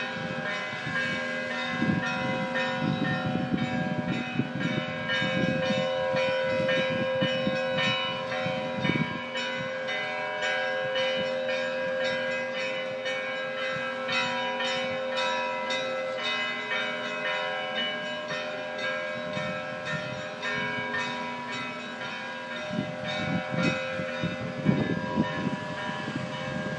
Церковь вместо таймера. пора начинать совещание.